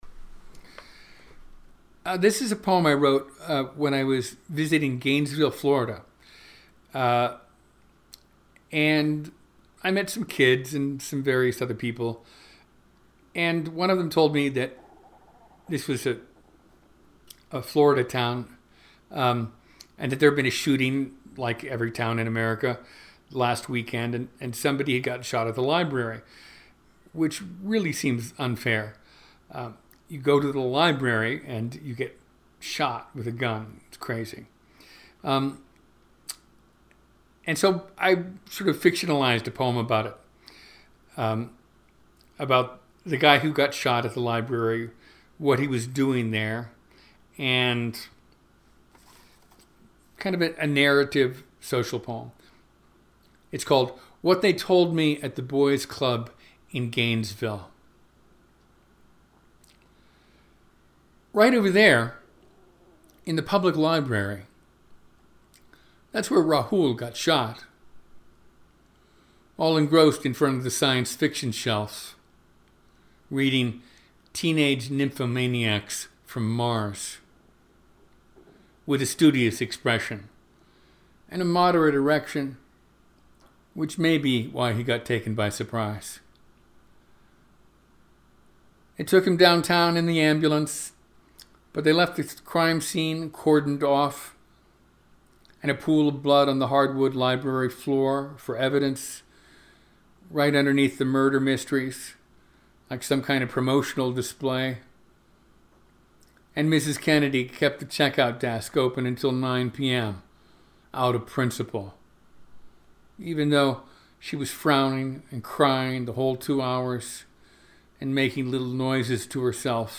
In the summer of 2018 Tony recorded himself reading twenty of those poems.